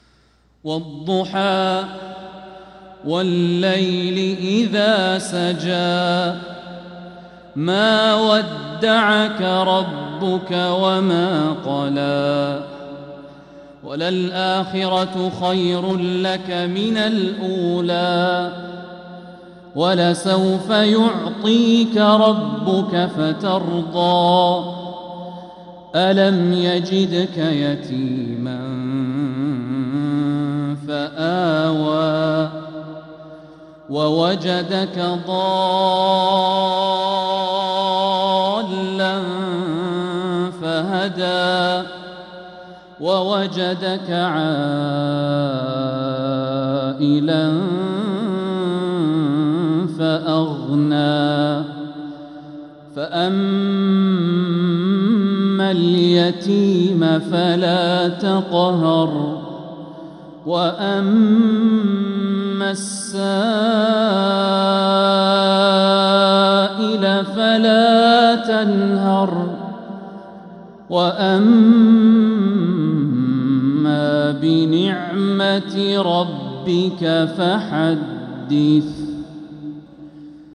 سورة الضحى | فروض ربيع الأخر 1446هـ
من الحرم المكي